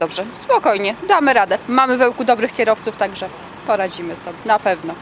Sytuację nieco lepiej oceniają piesi i podróżujący autobusami.